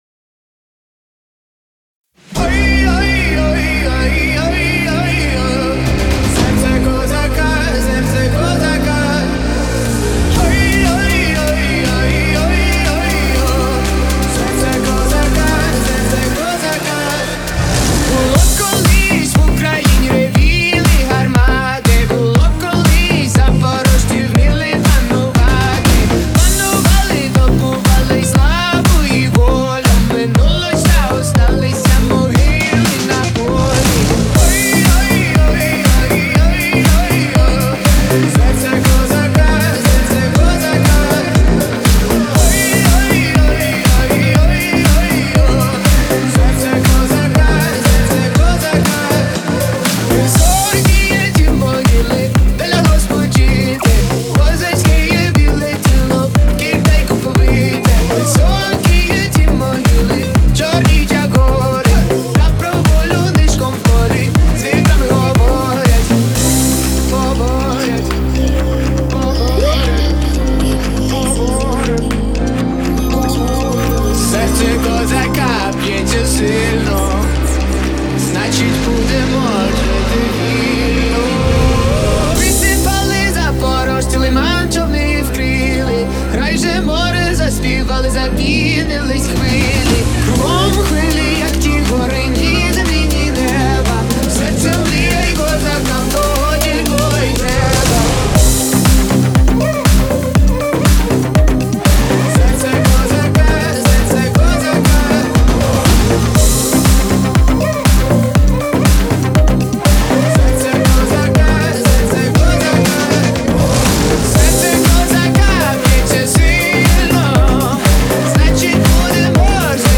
это мощное произведение в жанре фолк-рок